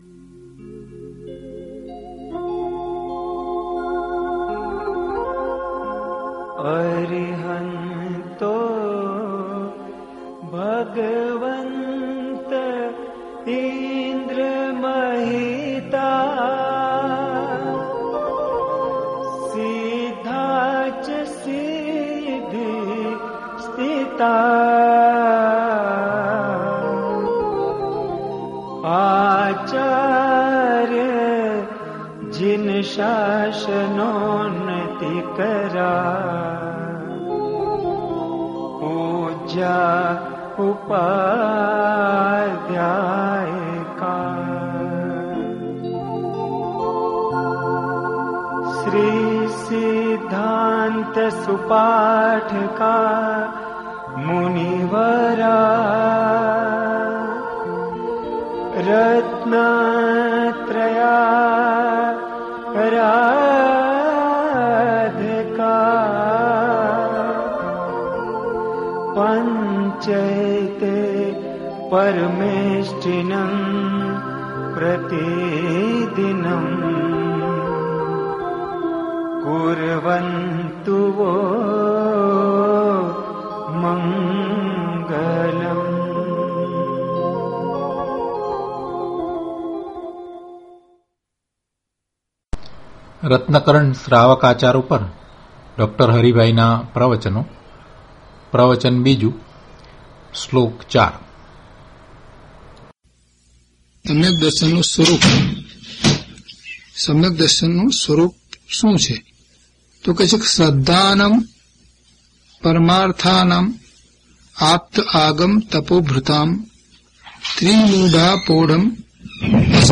DHP052 Ratnkarandak Shravakachar Shlok 4 Pravachan.mp3